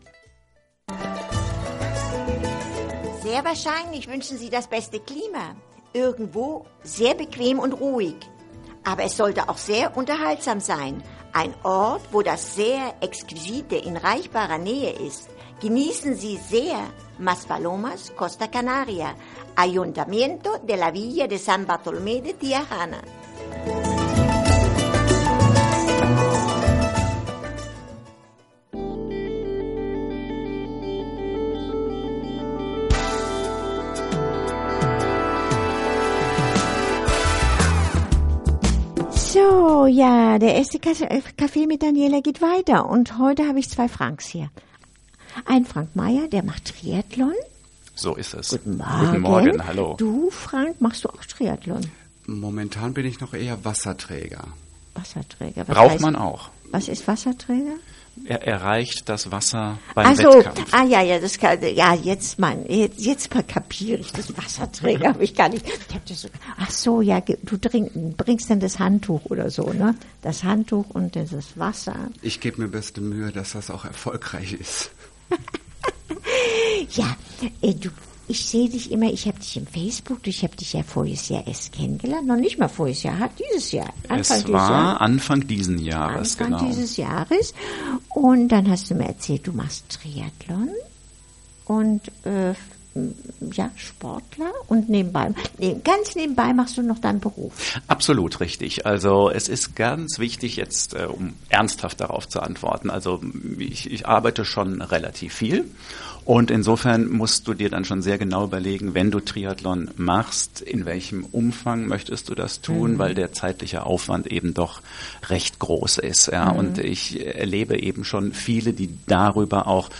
Block Tweets Feedback Medien Mein Auftritt bei Radio Dunas, Gran Canaria Radio Dunas